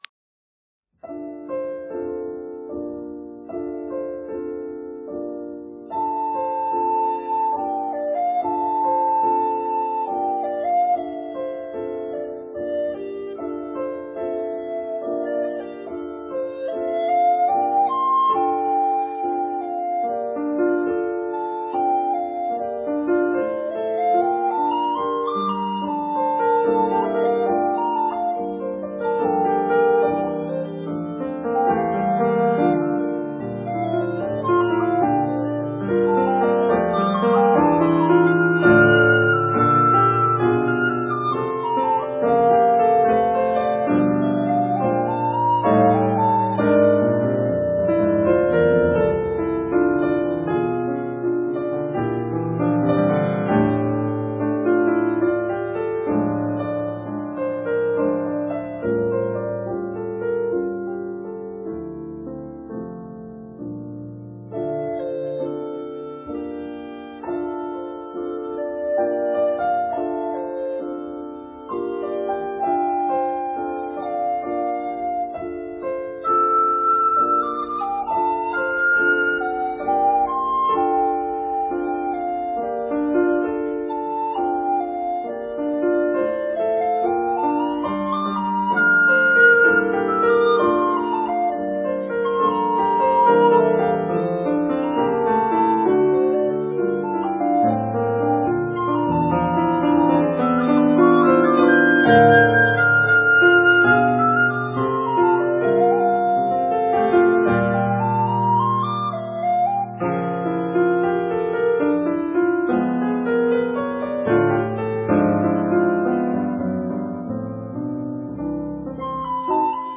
•(01) Concertino for treble recorder and piano, Op. 82